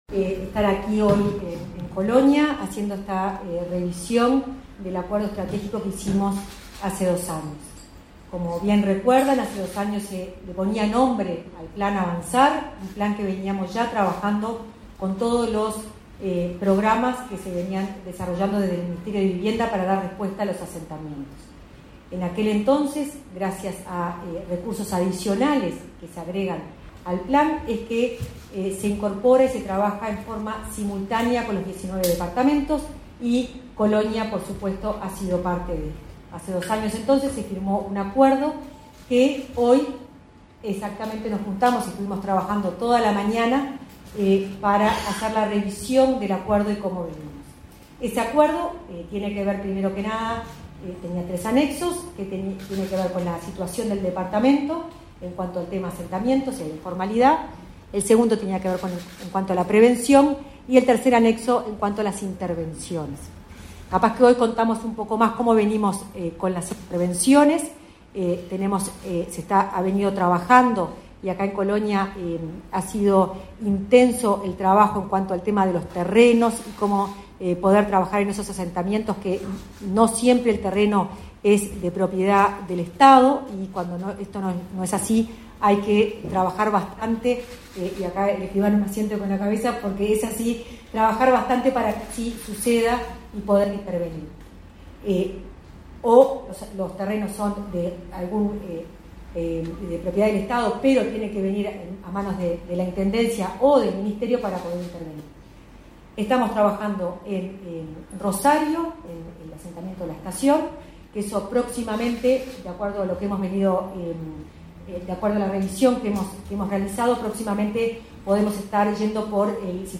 Palabras de la directora del MVOT, Florencia Arbeleche